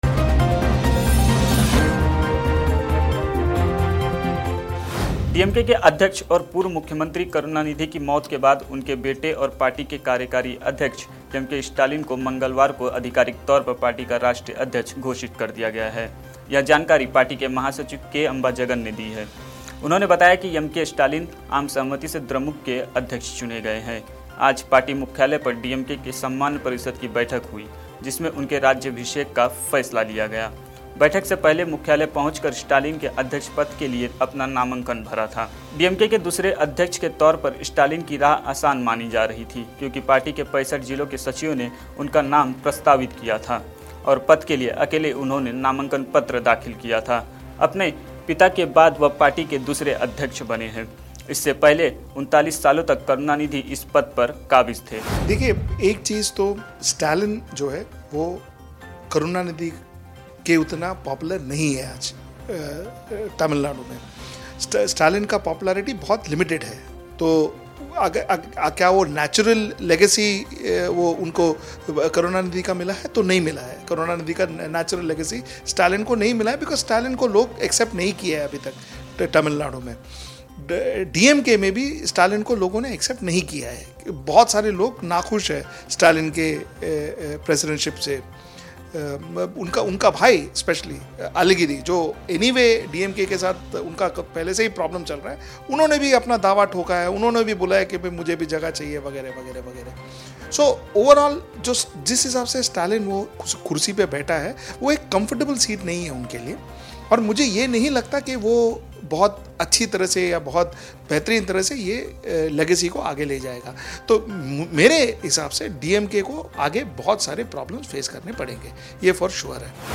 न्यूज़ रिपोर्ट - News Report Hindi / एमके स्टालिन के हांथ डीएमके की कमान, भाई अलागिरी ने दिखाई आंख